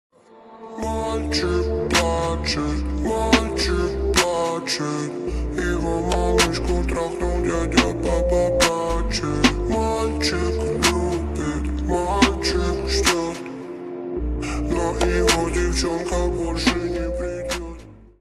• Качество: 192, Stereo
remix
грустные
спокойные
Trap
медленные
Slow